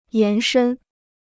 延伸 (yán shēn): to extend; to spread.